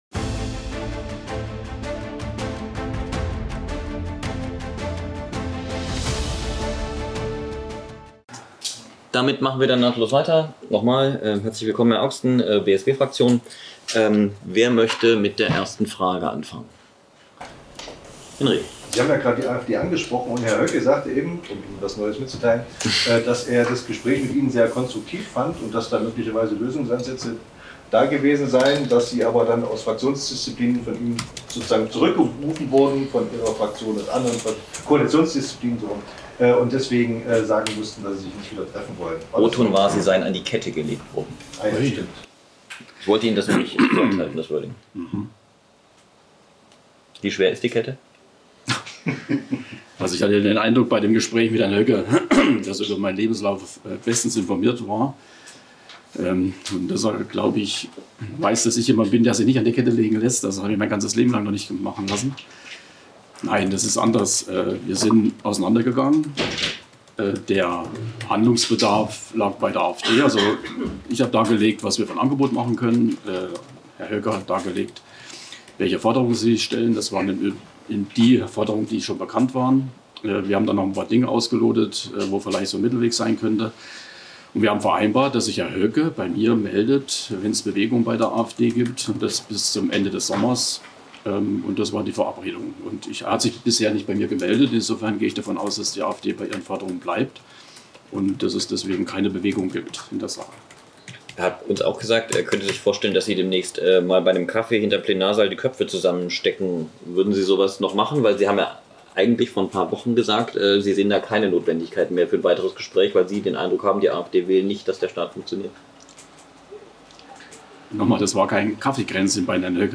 Die Fraktions- und die Landesvorsitzenden der drei Parteien besprachen dabei die politischen Schwerpunkte f�r das zweite Halbjahr. Th�ringens Ministerpr�sident Mario Voigt (CDU) stellte sie gemeinsam mit Finanzministerin Katja Wolf (BSW) und Innenminister Georg Maier (SPD) der �ffentlichkeit vor. Wir zeigen die Aufzeichnung der Pressekonferenz an dieser Stelle.